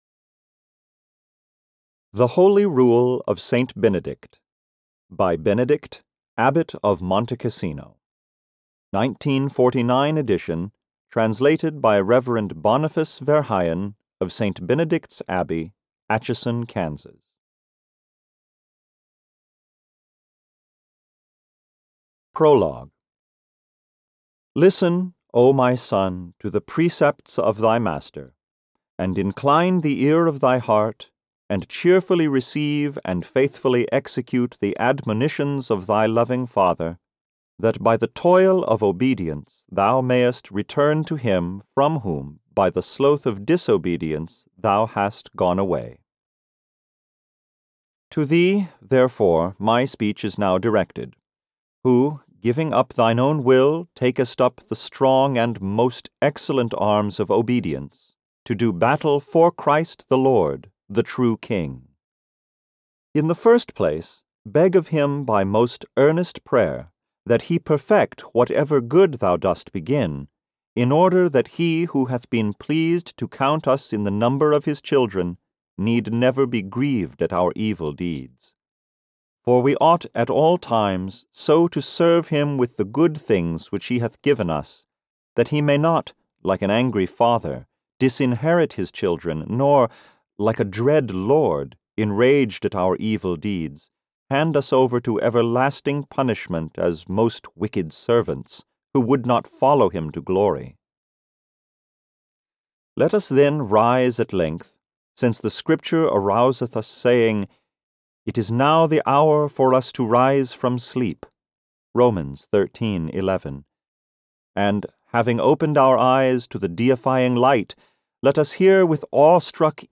The Holy Rule of St. Benedict Audiobook
2.5 Hrs. – Unabridged